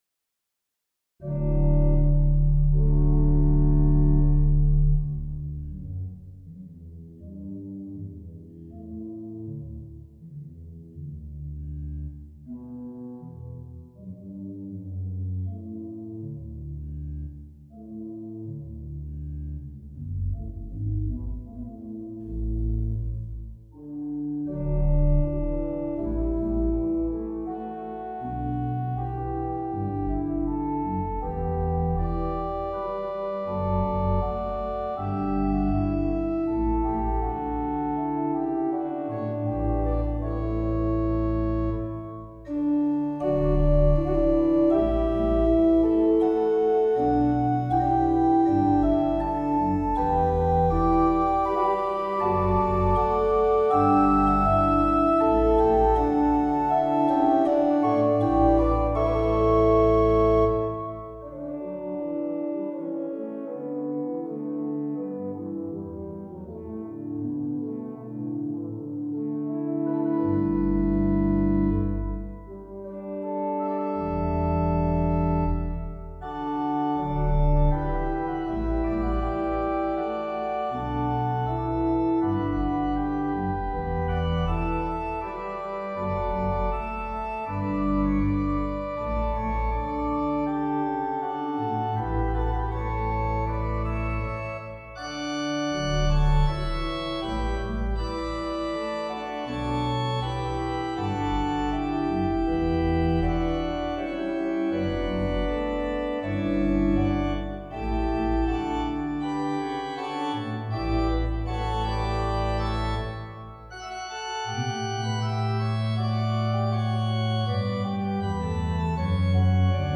for organ
Dividing a bar into regular irregularities was a thought had during our Italian language club, and so the title went from thought to germ to a working out of 5/4 meter, For the fun of it, and because music -- my mistress -- is feminine in Italian, as in Spanish, French, German and other languages.